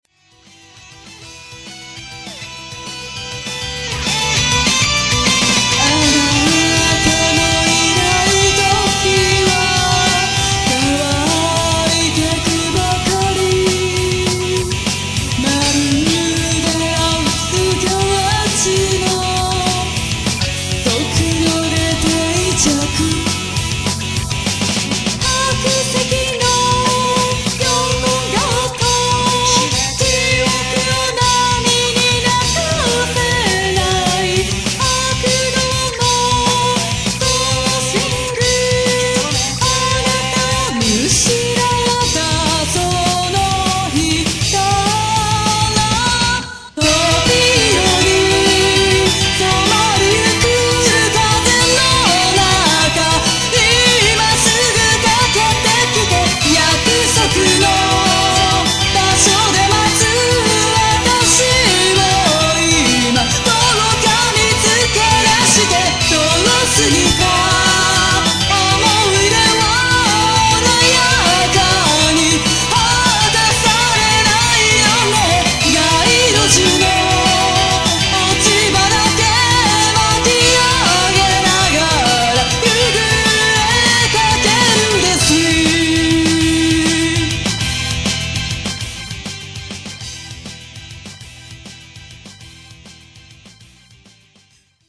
ビジュアル系バンド